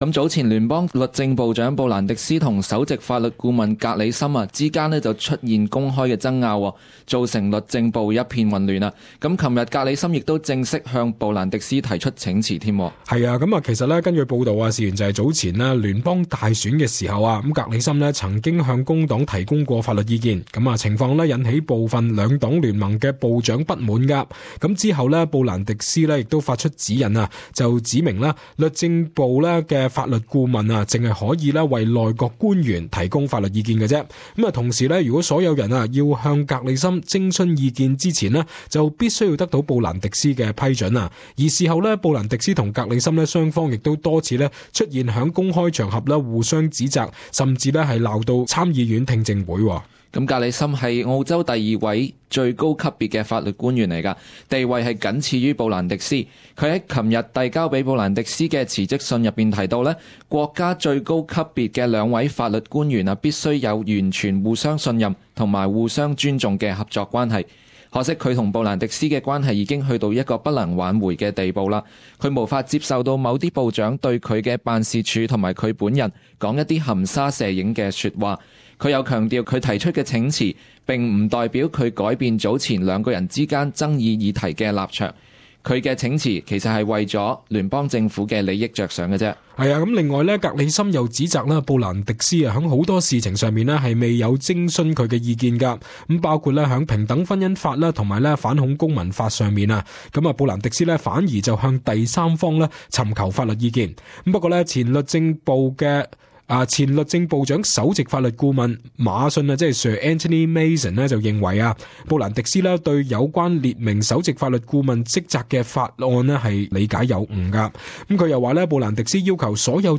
【时事报导】 不满律政部长处事手法 | 格里森请辞